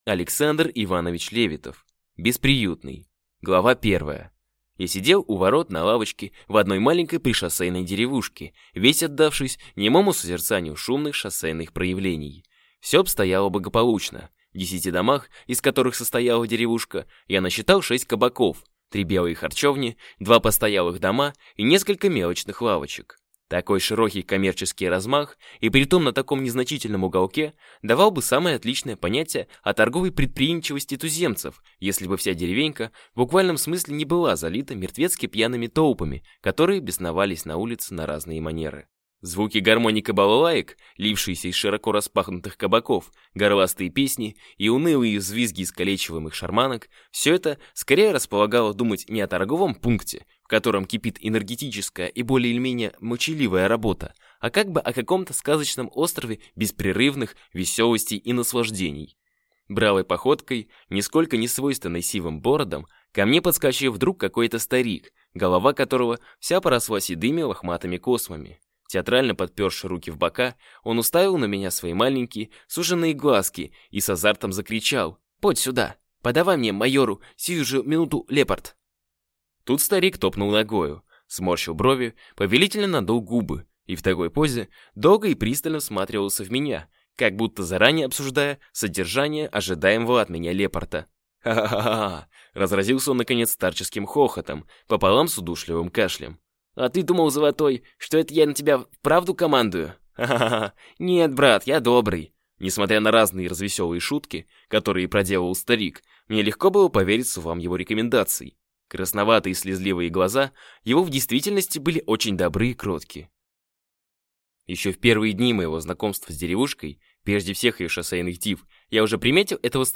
Аудиокнига Бесприютный | Библиотека аудиокниг